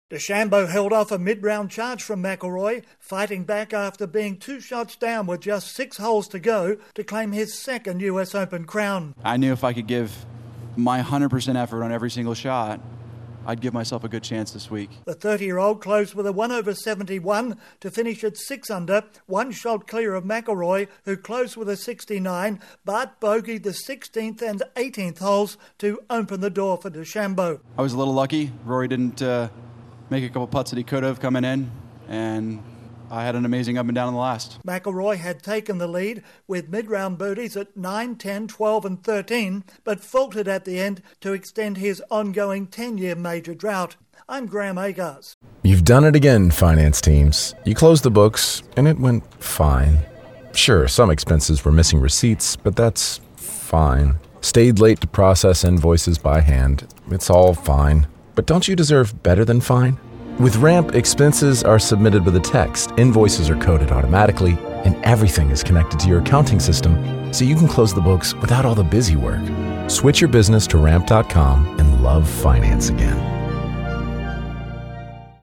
Bryson DeChambeau completes a dramatic one-shot victory over Rory McIlroy to claim the US Open Championship in Pinehurst North Carolina. Correspondent